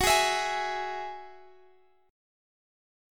Listen to F#7sus2 strummed